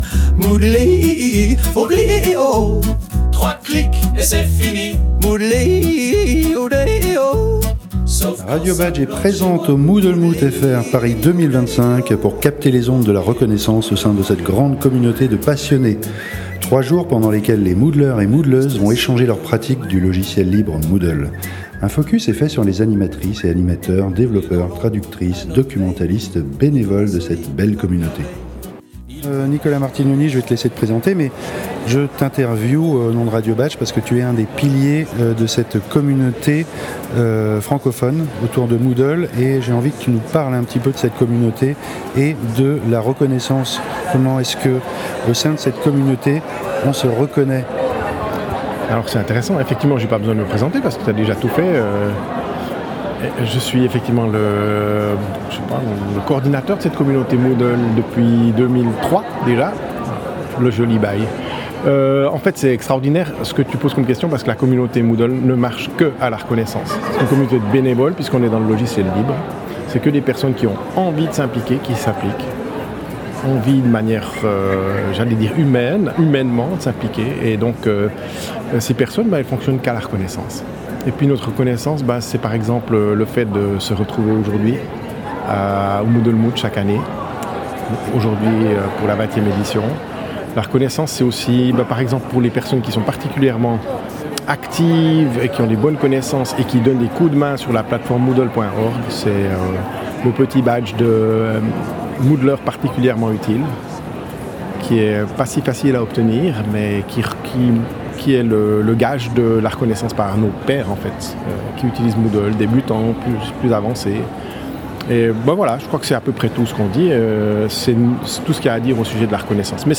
3. En direct du Moot